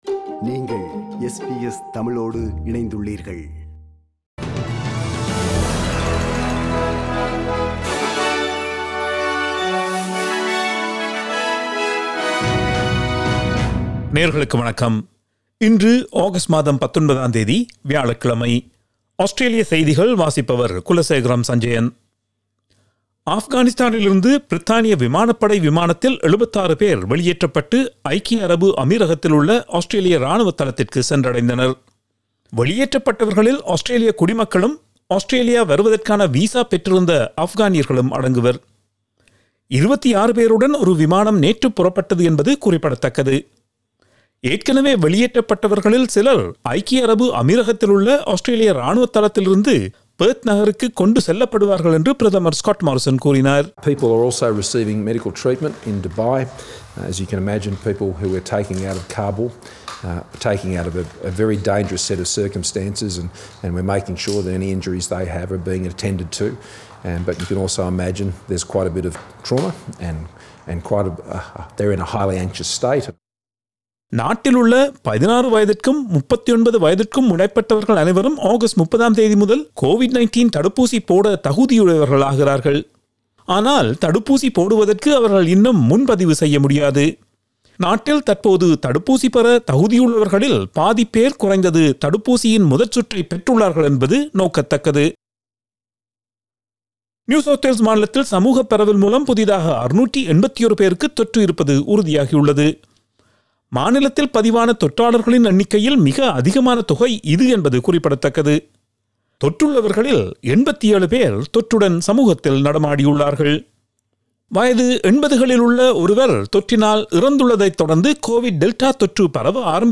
Australian news bulletin for Thursday 19 August 2021.